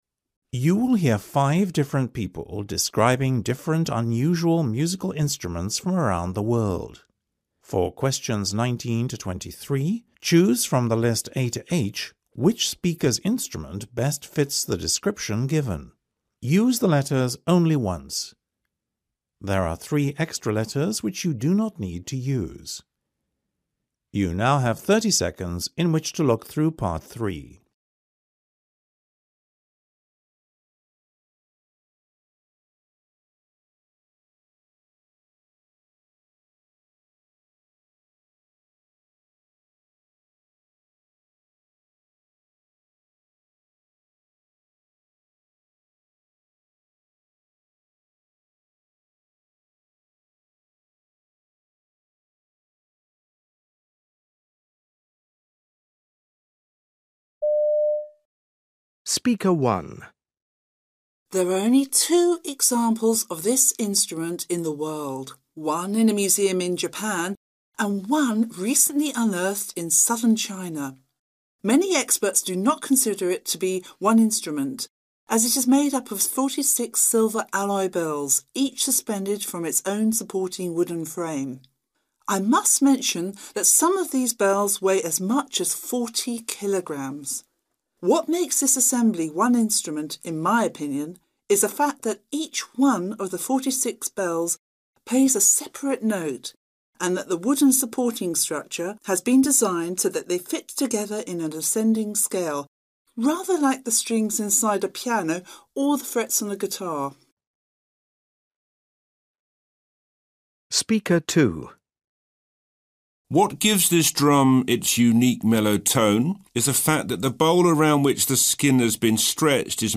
You will hear five different people describing different unusual musical instruments from around the world.